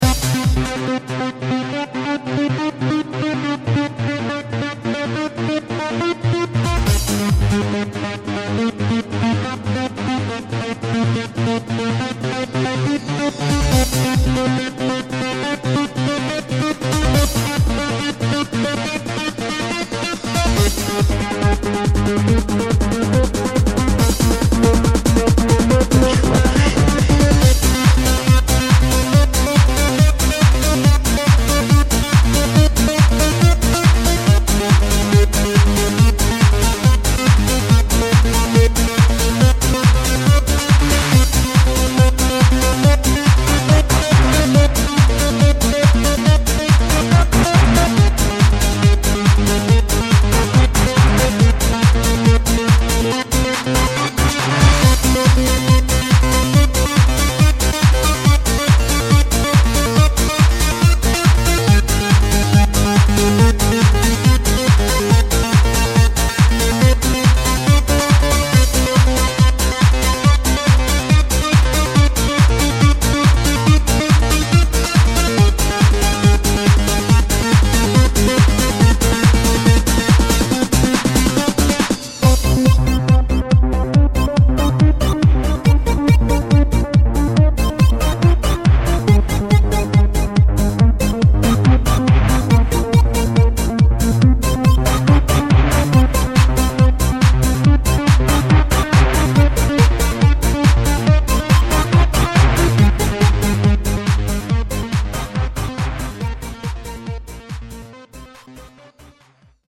Progressive House, Techno